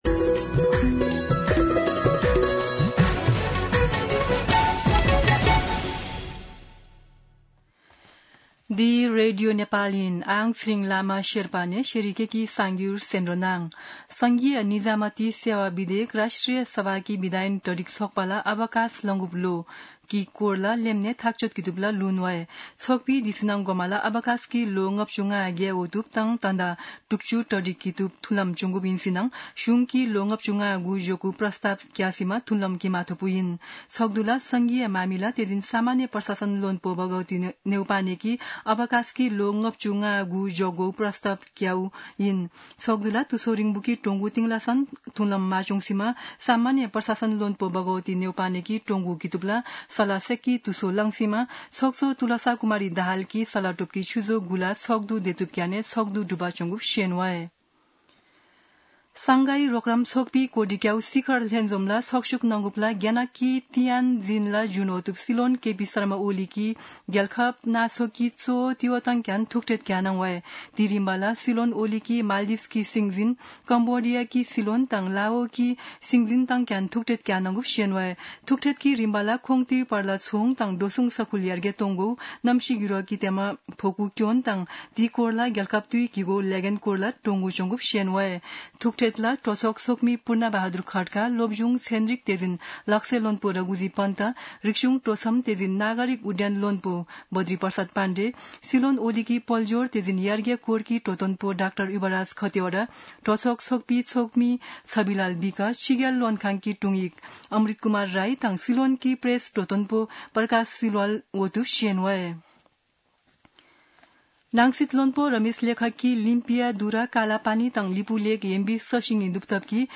शेर्पा भाषाको समाचार : १६ भदौ , २०८२
Sherpa-News-16.mp3